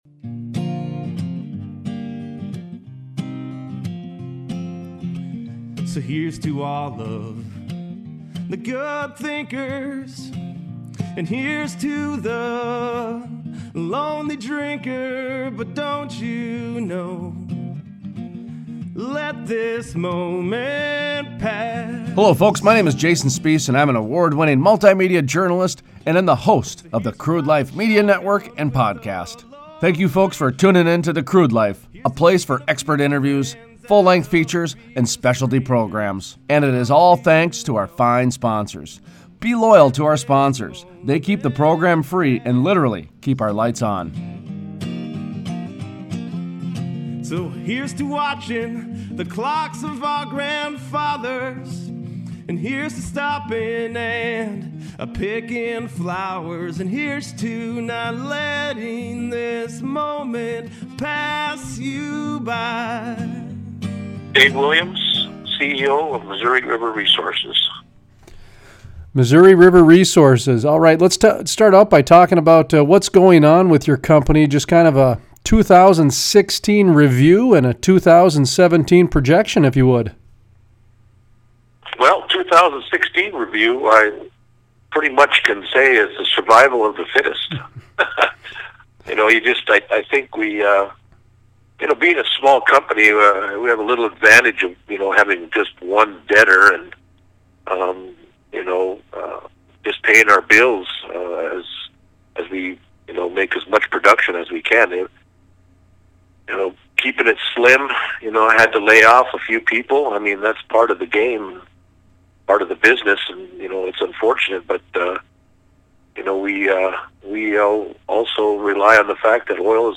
Full Length Interview: An update on oil from the reservation - The Crude Life